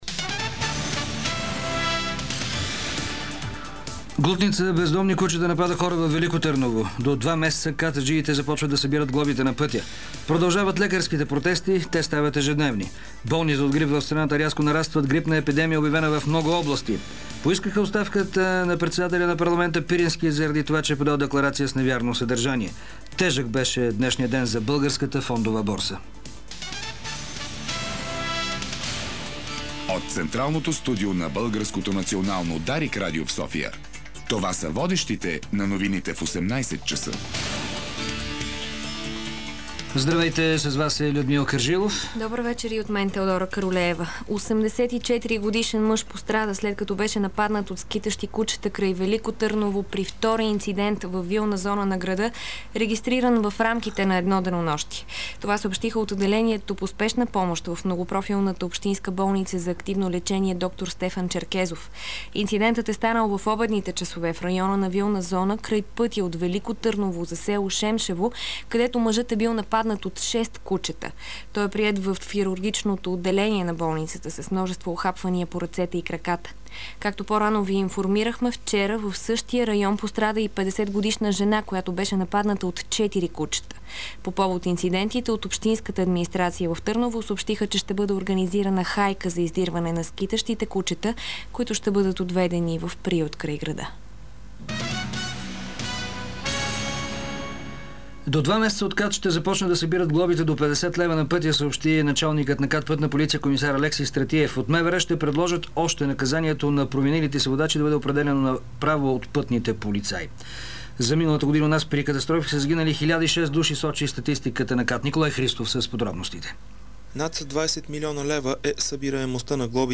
Обзорна информационна емисия - 22.01.2008